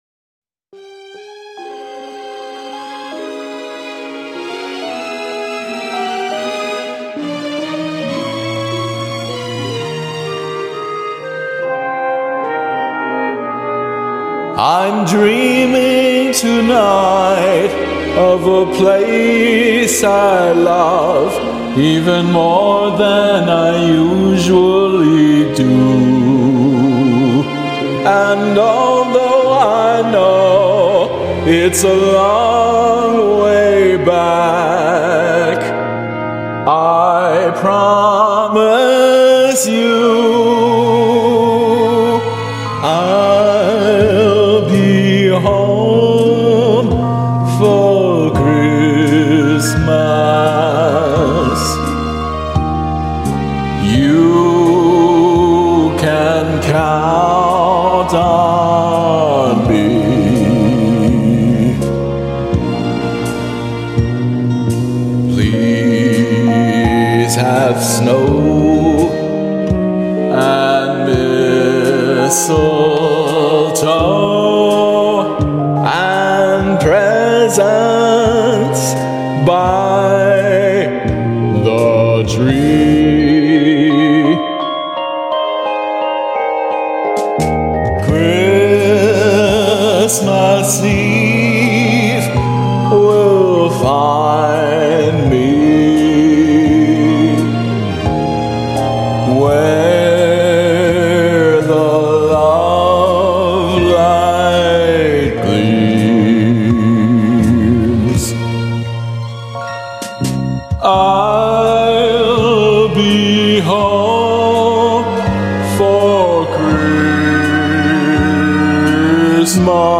karaoke arrangement
I'm singing to a karaoke arrangement